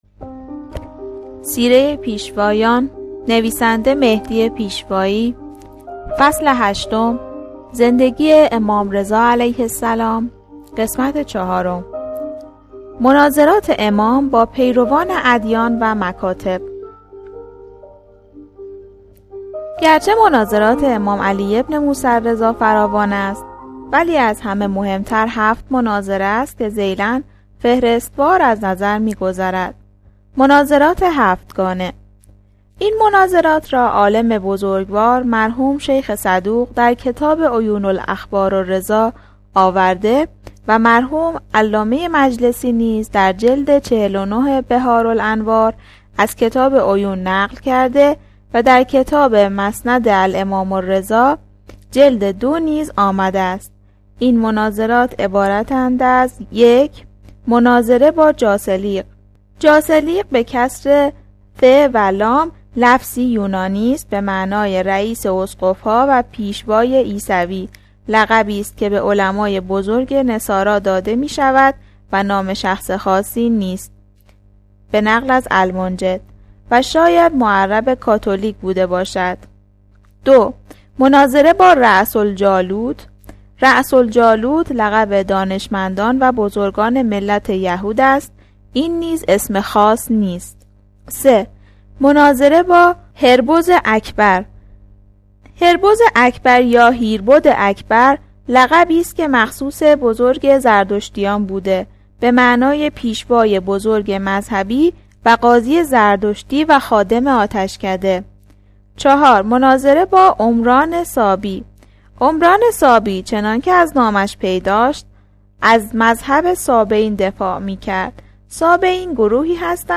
دانلود کتاب صوتی سیره ی پیشوایان – مهدی پیشوایی